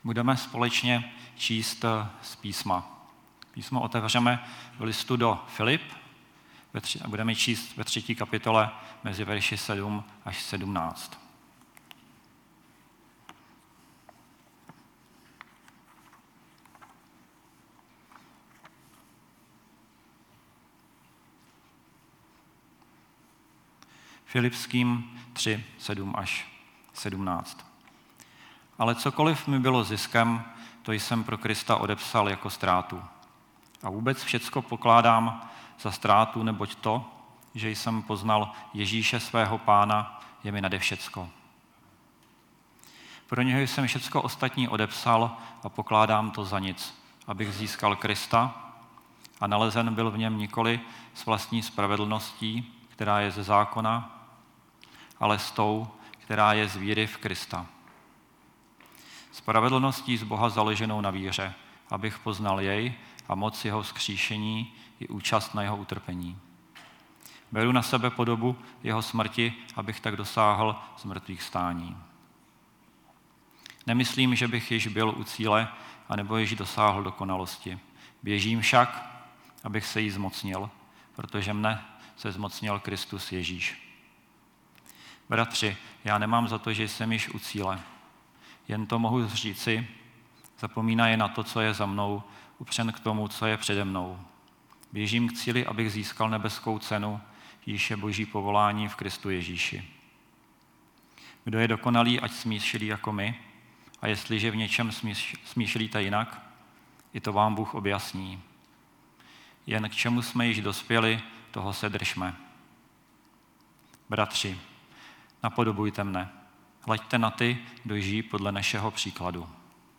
Nedělní bohoslužba